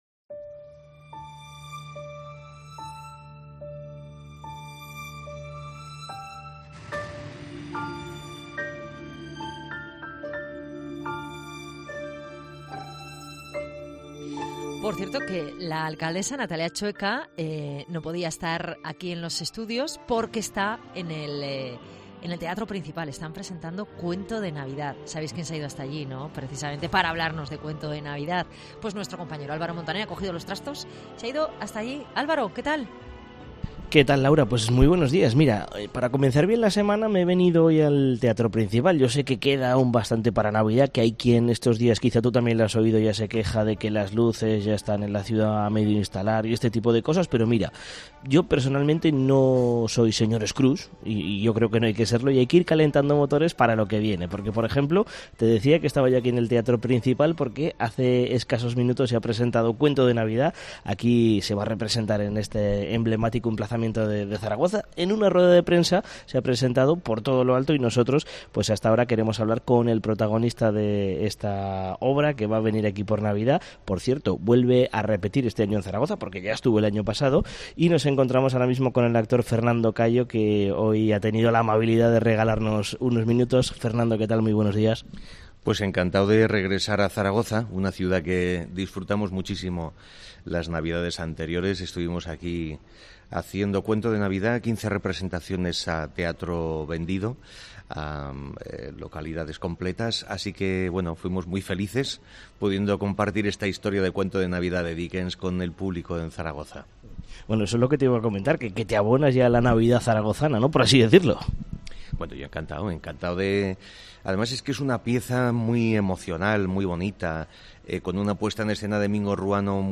Entrevista a Fernando Cayo, actor que volverá este año al Teatro Principal con 'Cuento de Navidad'